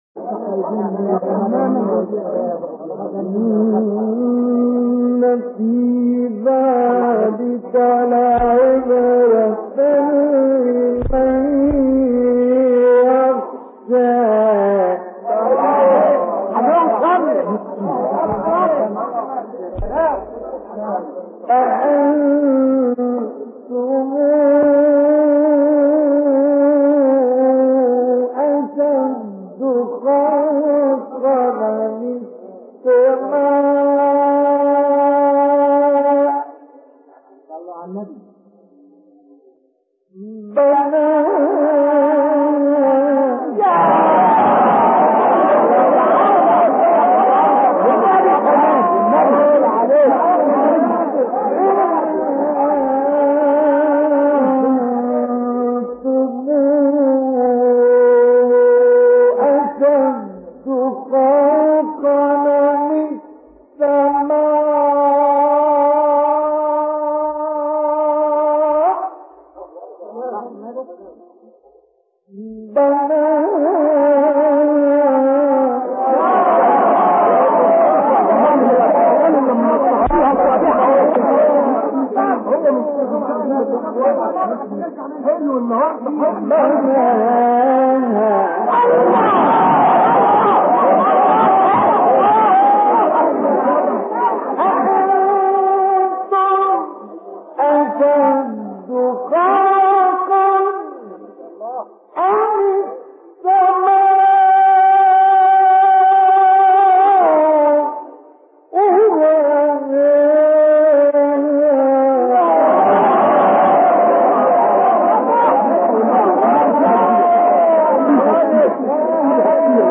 حیات اعلی :: دریافت 2- نازعات = بوستان تلاوت 98